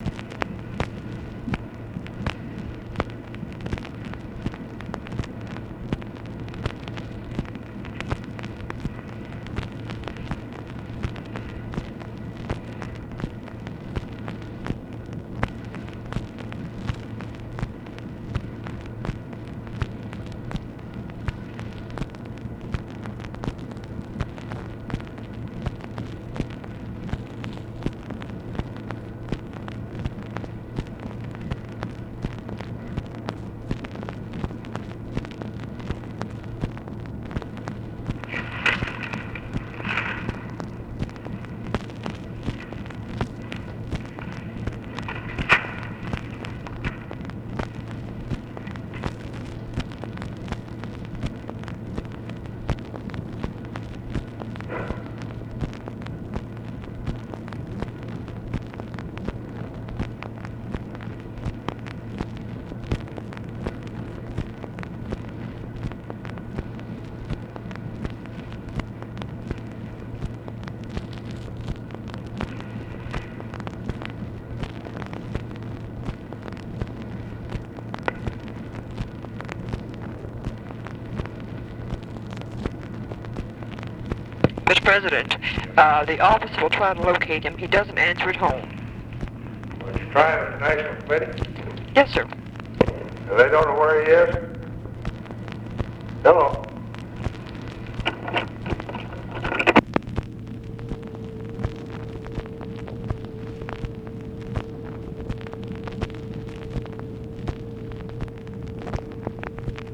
Conversation with TELEPHONE OPERATOR, August 14, 1964
Secret White House Tapes | Lyndon B. Johnson Presidency